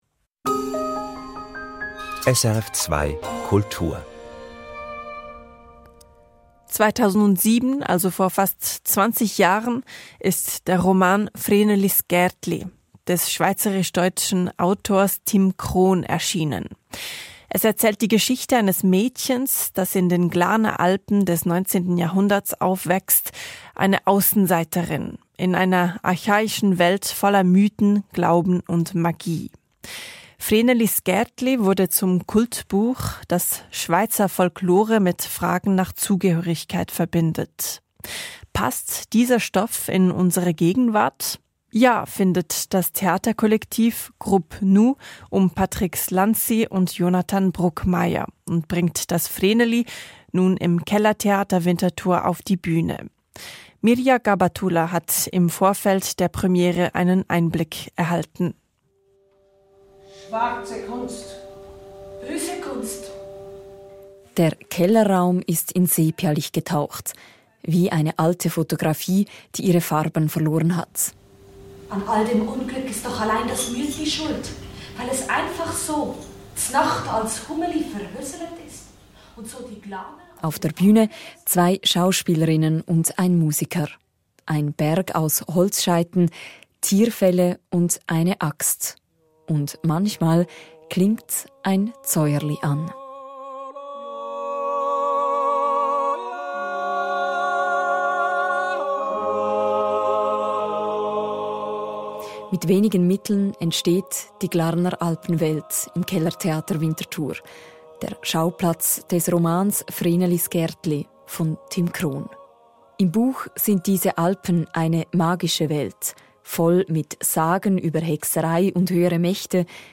Bericht SRF Kultur, 13. März 2026